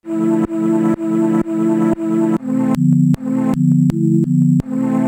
Pops and clicks when switching pads on same "channel"
When I tap pad 1 and then pad 2(or 3 or 4) afterwards there is a pop/click(sounds like DC offset or the sample isnt at the 0 point).
I did a quick recording to demonstrate the noise Im seeing… here is what the transition looks like: